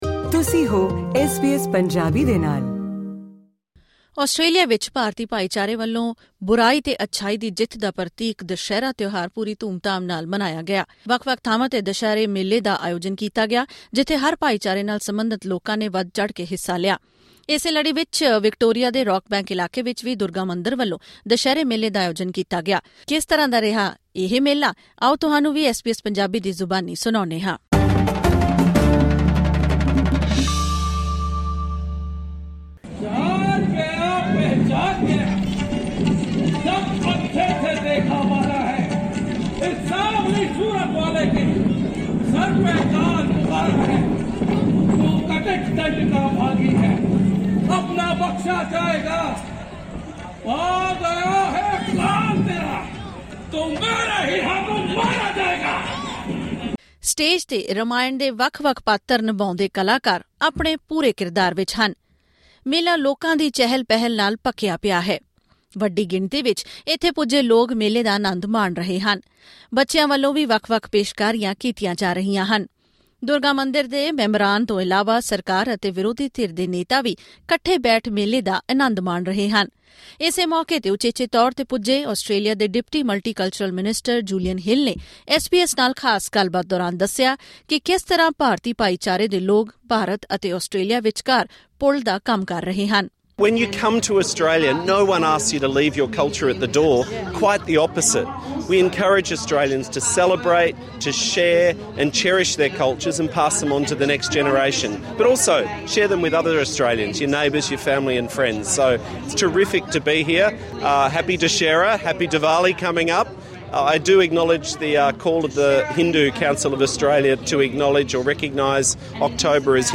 ਐਸ ਬੀ ਐਸ ਪੰਜਾਬੀ ਨਾਲ ਖ਼ਾਸ ਗੱਲਬਾਤ ਕਰਦਿਆਂ ਆਸਟ੍ਰੇਲੀਆ ਦੇ ਡਿਪਟੀ ਮਲਟੀਕਲਚਰਲ ਮਨਿਸਟਰ ਜੂਲੀਅਨ ਹਿੱਲ ਨੇ ਕਿਹਾ ਕਿ ਆਸਟ੍ਰੇਲੀਆ ਵਿੱਚ ਵਸਦੇ ਭਾਰਤੀ ਭਾਈਚਾਰੇ ਦੇ ਲੋਕ ਦੋਹਾਂ ਦੇਸ਼ਾਂ ਵਿੱਚਕਾਰ ਪੁਲ ਦਾ ਕੰਮ ਕਰਦੇ ਹਨ।